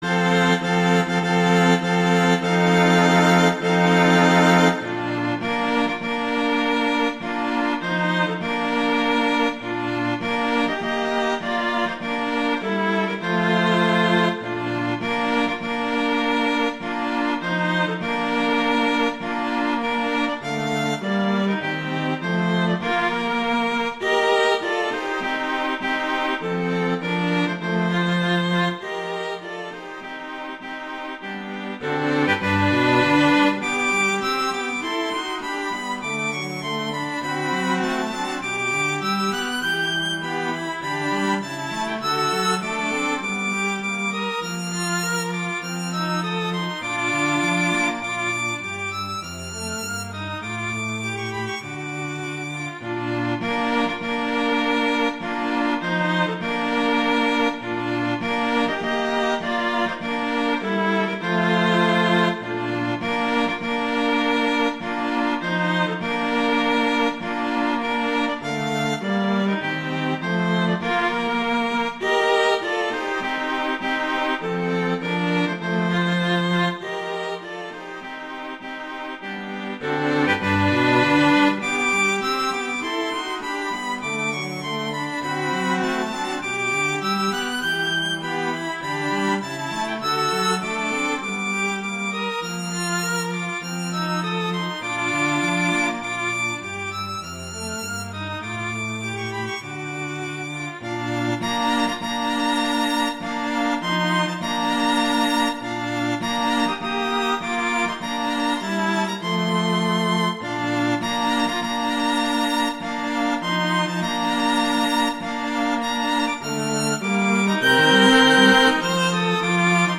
arrangements for string trio
wedding, traditional, classical, festival, love, french